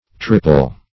tripel - definition of tripel - synonyms, pronunciation, spelling from Free Dictionary Search Result for " tripel" : The Collaborative International Dictionary of English v.0.48: Tripel \Trip"el\, n. (Min.)